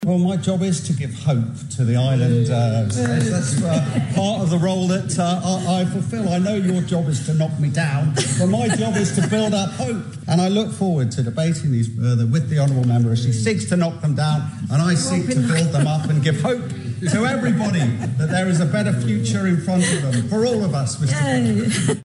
Mr Cannan dismissed those concerns: